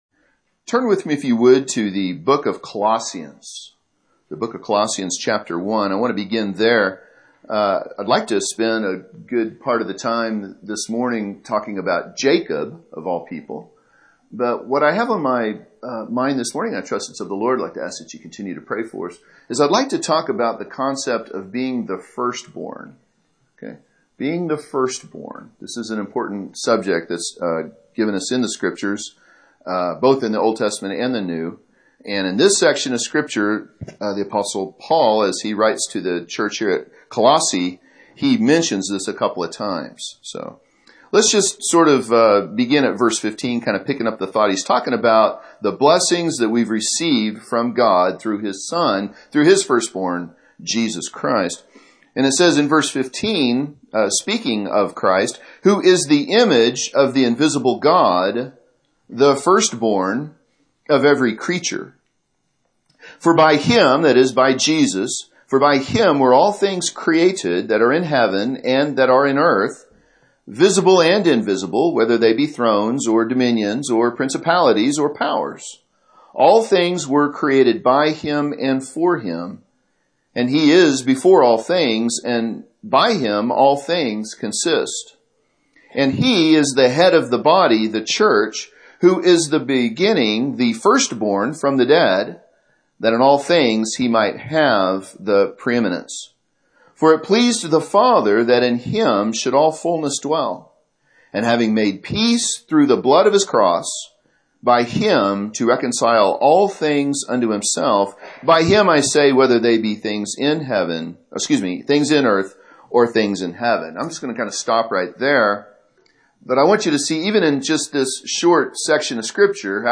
Sermons preached in other churches • Page 14
This sermon was recorded at Oxford Primitive Baptist Church Located in Oxford,Kansas